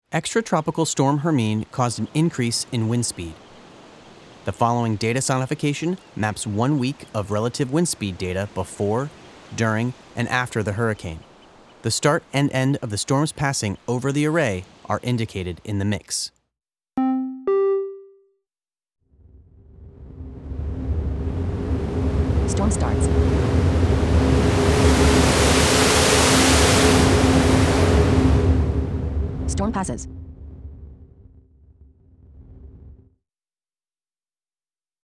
Storm-Hermine-Audio-Display-7-Wind-Speed-Sonification.mp3